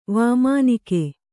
♪ vāmānike